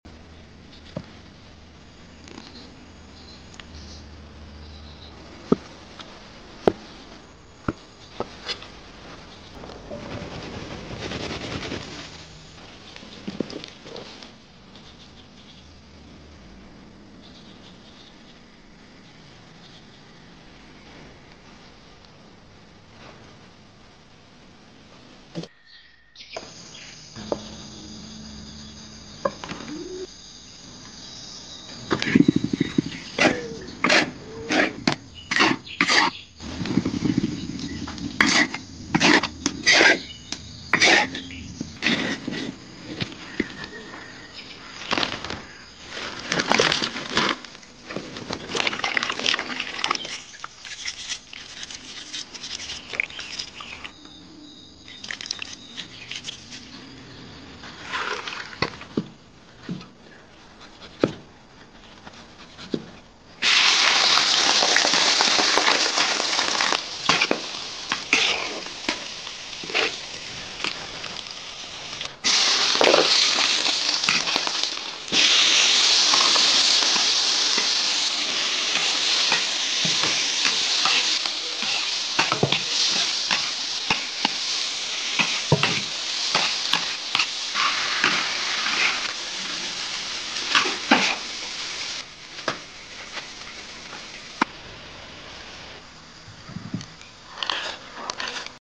Grinding Soybeans Into Tofu With sound effects free download